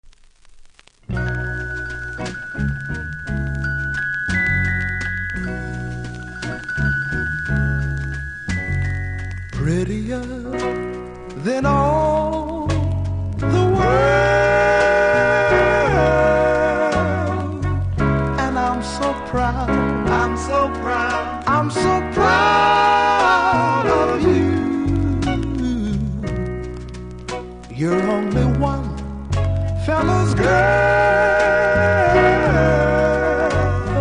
〜60'S MALE GROUP